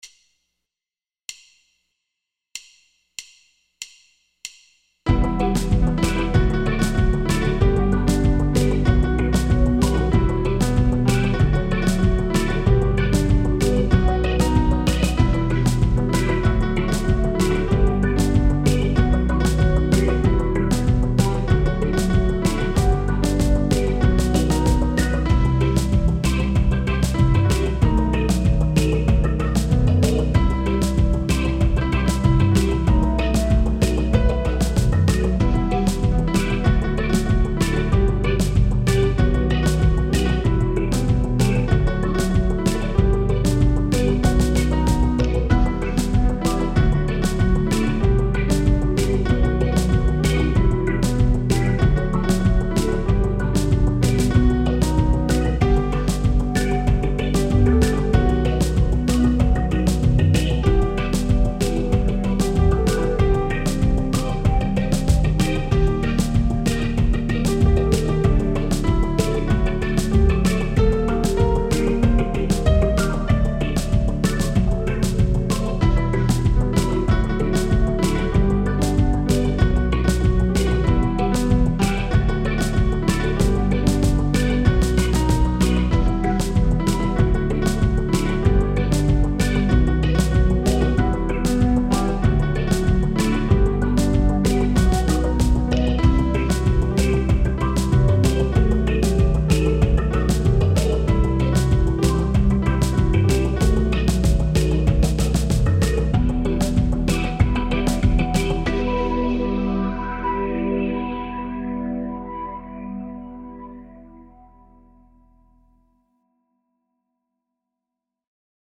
Audio amb la base musical